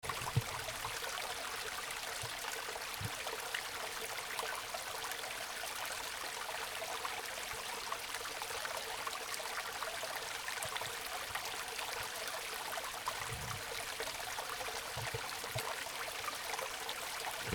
Pour vous mettre dans l’ambiance, voici un son capté et un texte qui tente de décrire ce que je cherche …
Le bruit blanc de l’eau tournoie dans la tête, la trame de l’espace se délite…